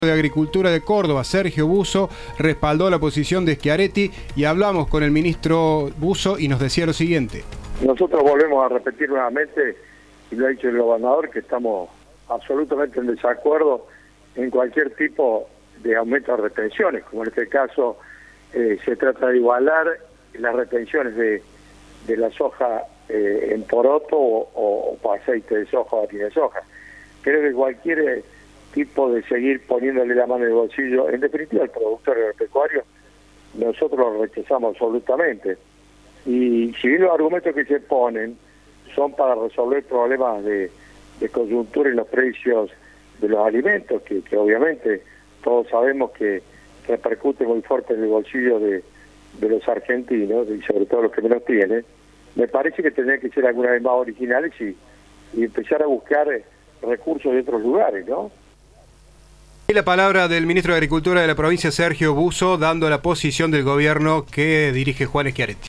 El ministro de Agricultura y Ganadería de Córdoba habló con Cadena 3 sobre la medida que tomó el Gobierno nacional y sostuvo que tienen que "ser más originales para buscar recursos de otros lugares".